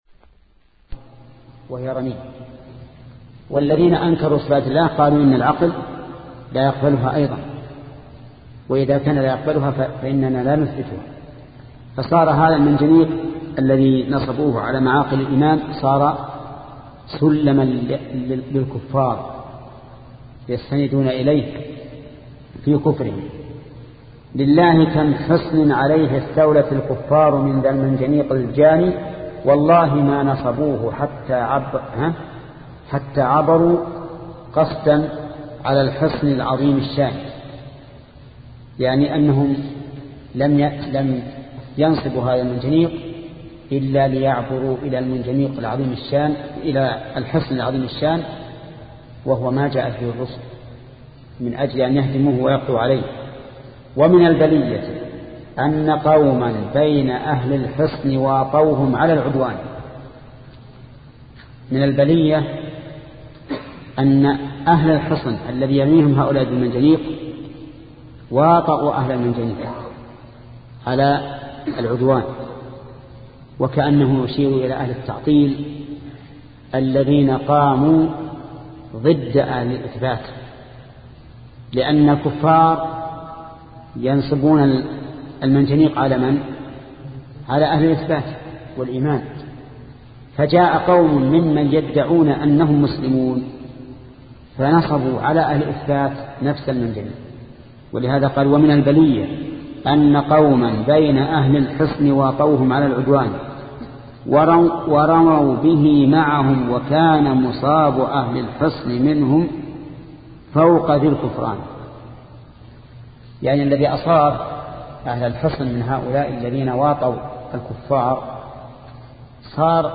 شبكة المعرفة الإسلامية | الدروس | التعليق على القصيدة النونية 32 |محمد بن صالح العثيمين